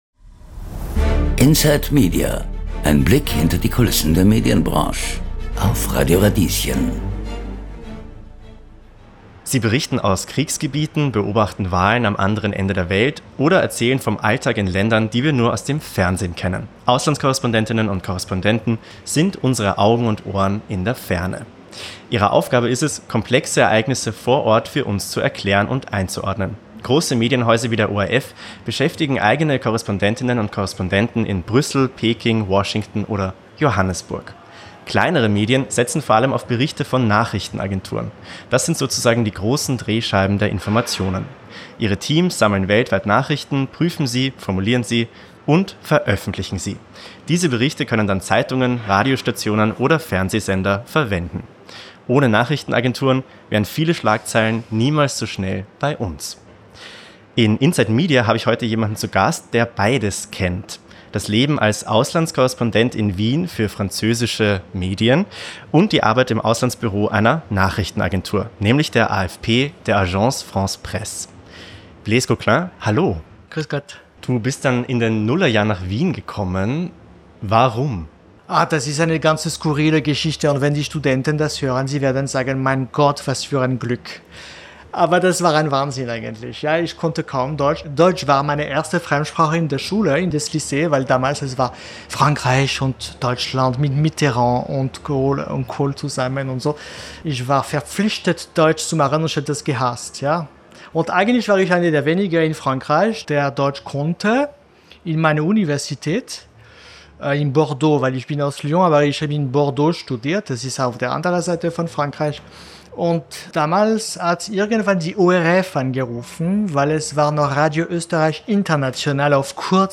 Dieser Podcast ist ein Ausschnitt aus der Inside Media-Radiosendung vom 27. Juni 2025. Die Sendung ist Teil des Radio Radieschen-Summer Specials 2025 aus dem Wiener Riesenrad.